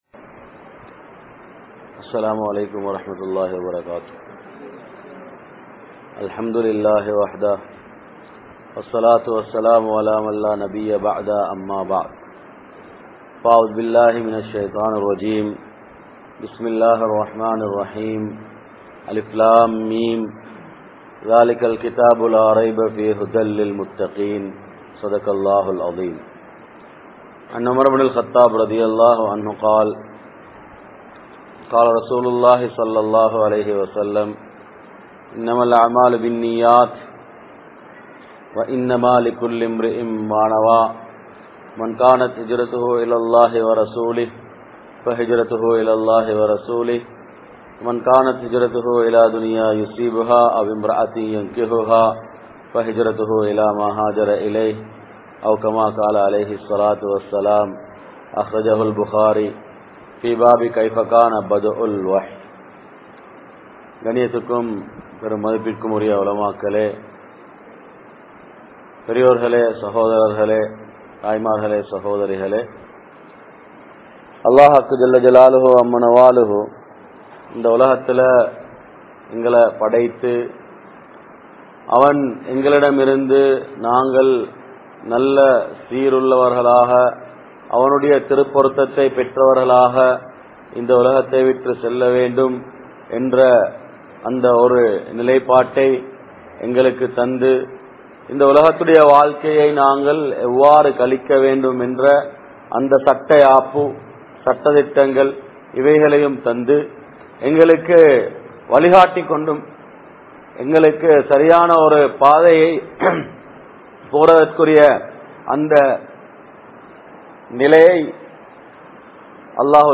Vaalifam (வாலிபம்) | Audio Bayans | All Ceylon Muslim Youth Community | Addalaichenai